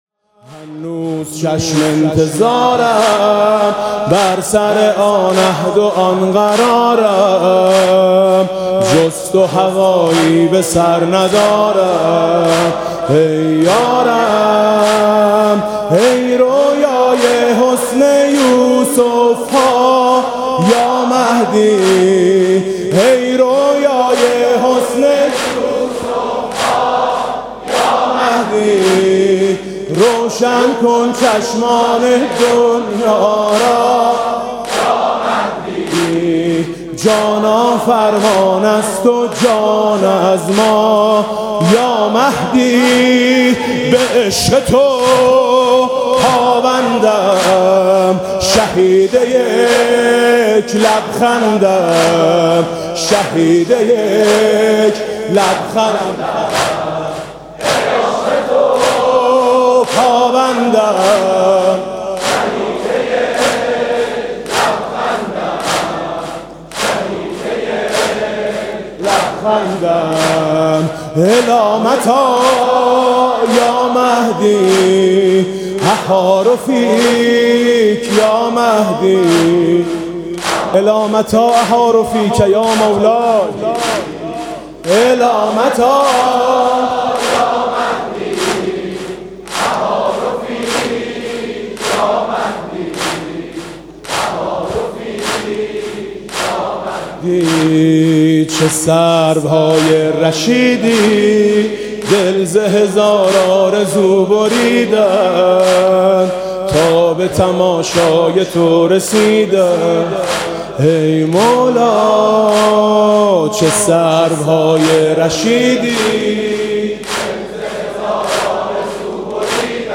دم پایانی محرم سال ۱۴۰۱